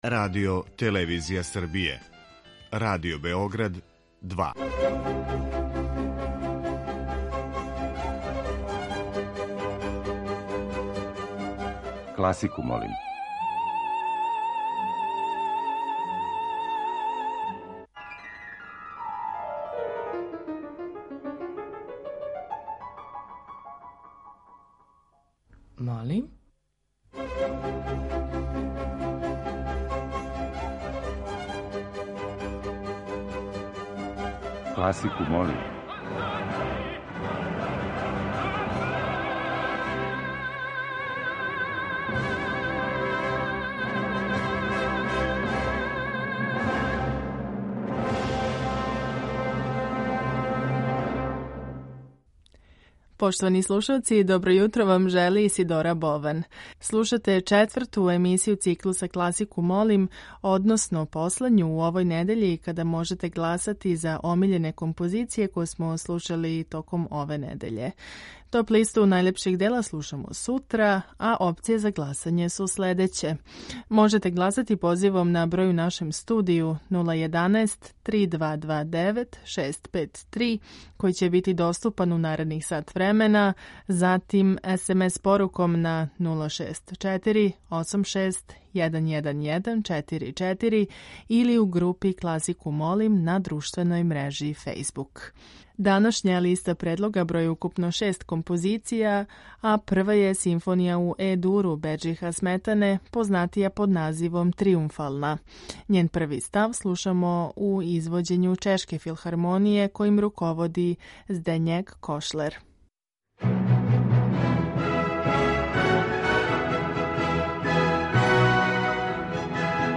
Избор за топ-листу класичне музике Радио Београда 2
Барокне свите